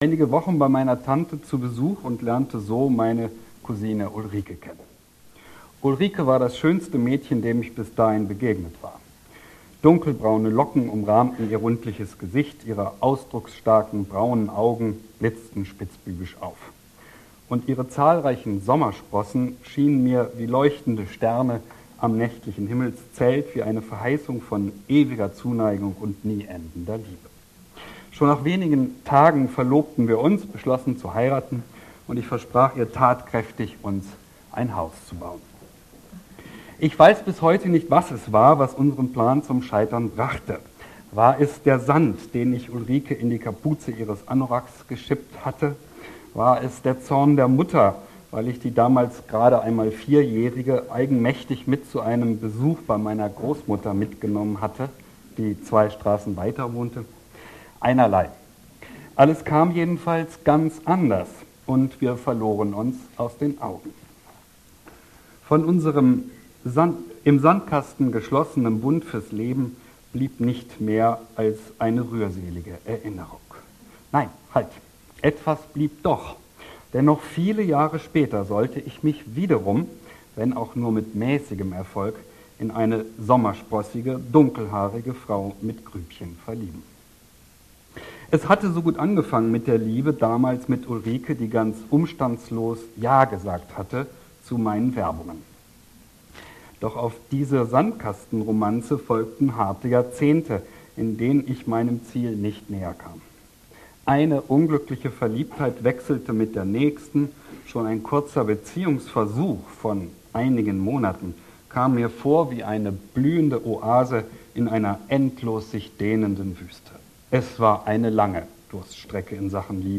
Vortrag